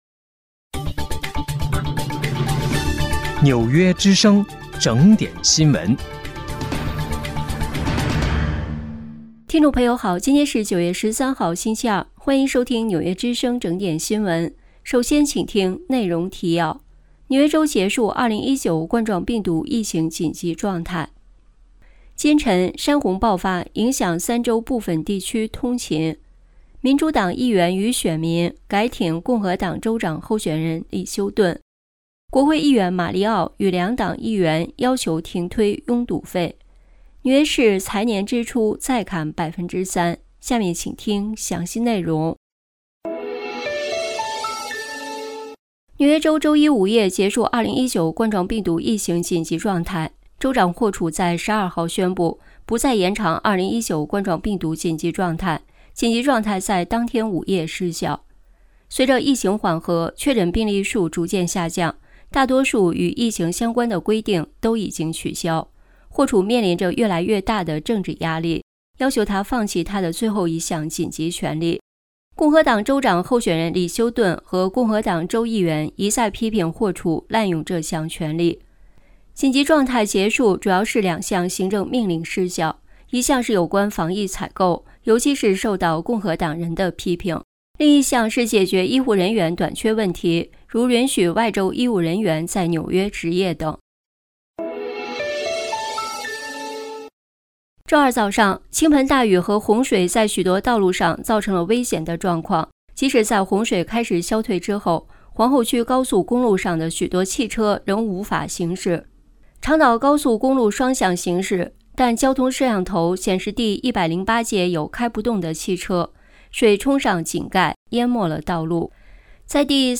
9月13号(星期二)纽约整点新闻。